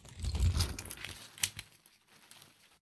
umbrella2.ogg